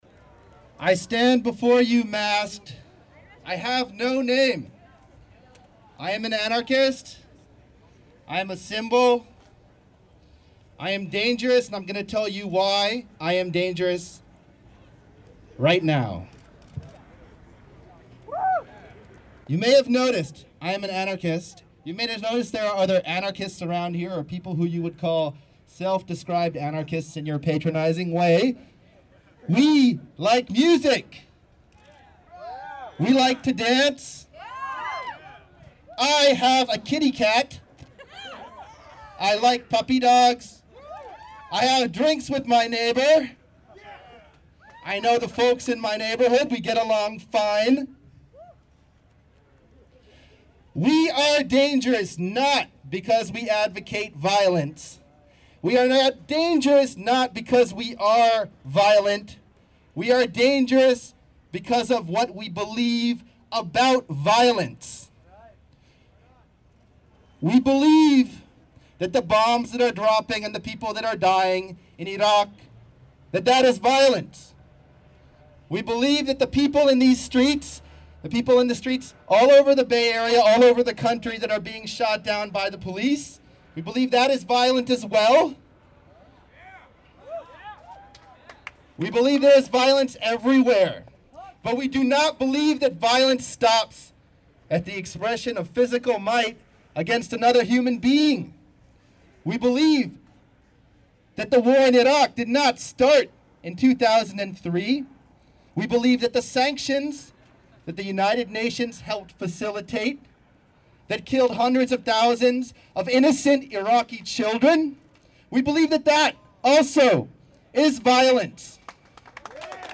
Audio of Masked Anarchist Addressing Assembly in Lytton Square